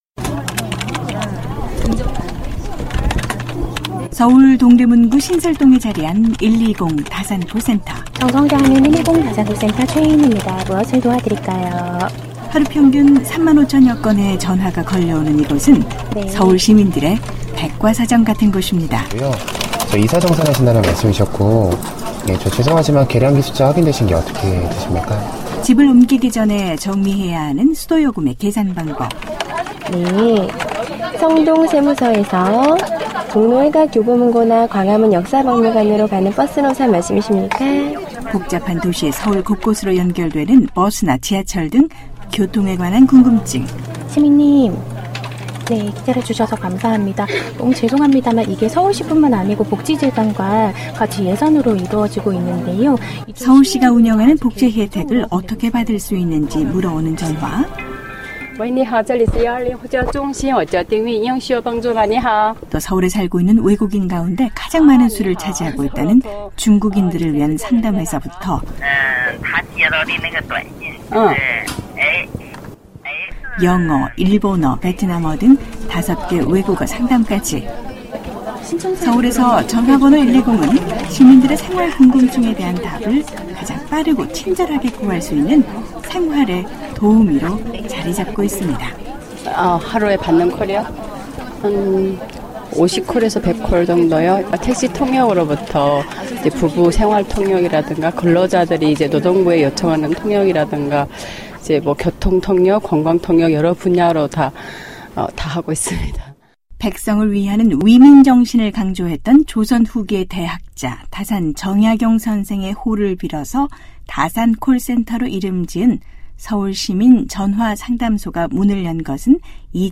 손님은 ‘왕’이라는 말처럼 시민을 귀한 ‘손님’처럼 생각한다는 서울시가 120전화 상담소를 만들어 하루 24시간, 365일 시민들의 궁금증을 해결해주고 있다고 합니다. ‘안녕하세요. 서울입니다’ 서울시민들의 생활 도우미 ‘120 다산콜센터’를 찾아가봤습니다.